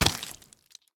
sounds / mob / drowned / step4.ogg
step4.ogg